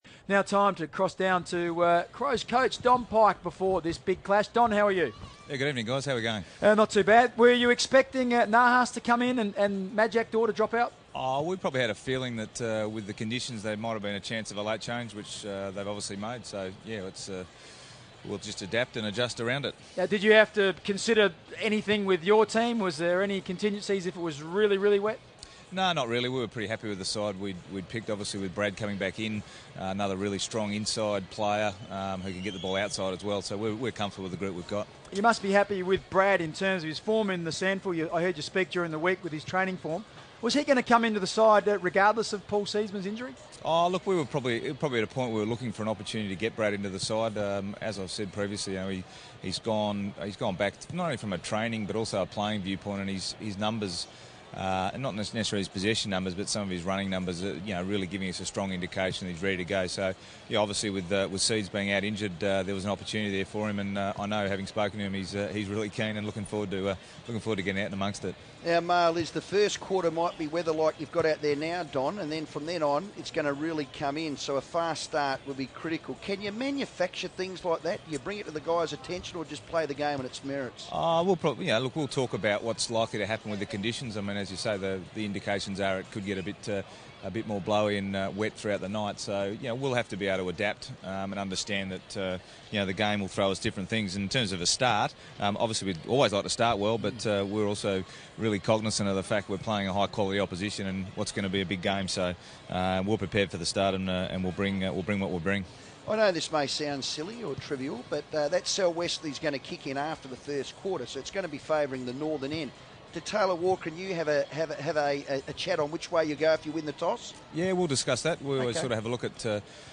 Don Pyke on FIVEaa pre-match show